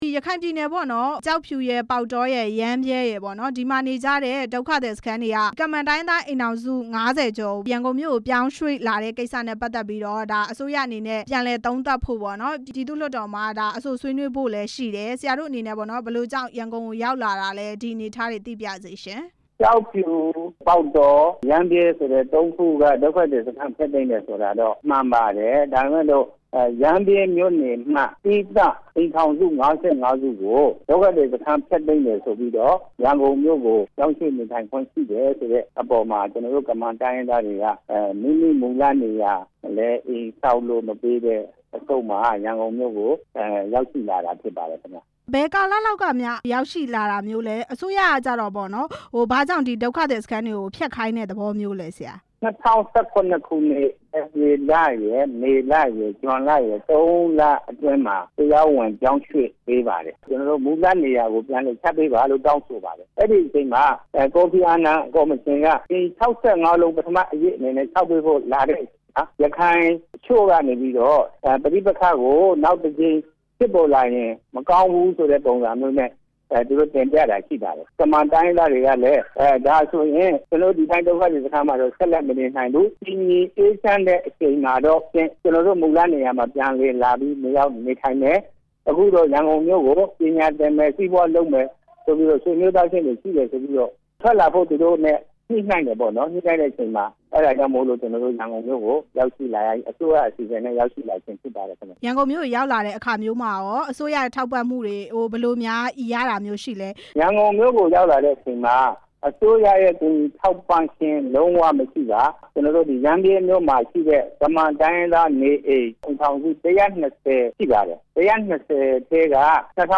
ရန်ကုန်မြို့ရောက် ကမန်တိုင်းရင်းသားတွေရဲ့ အခြေအနေအကြောင်း မေးမြန်းချက်